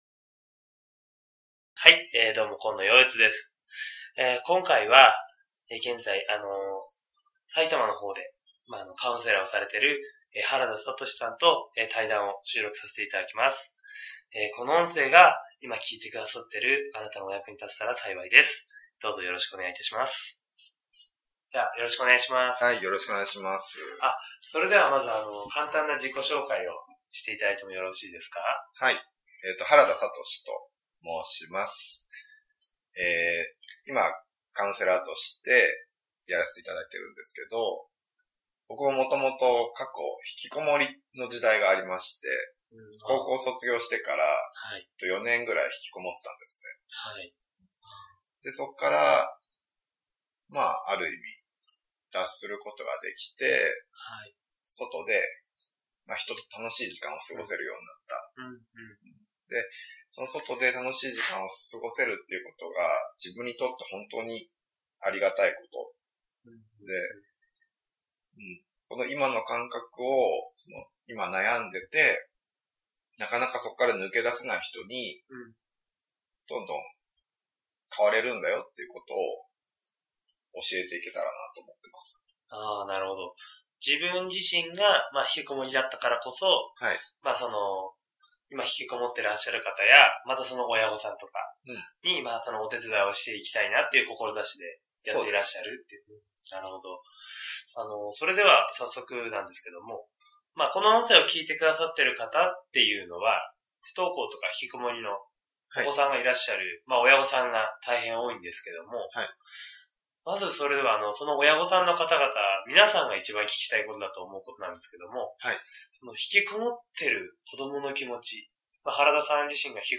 不登校・引きこもりの音声対談セミナーを公開します。